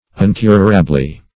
uncurably - definition of uncurably - synonyms, pronunciation, spelling from Free Dictionary Search Result for " uncurably" : The Collaborative International Dictionary of English v.0.48: Uncurably \Un*cur"a*bly\, adv.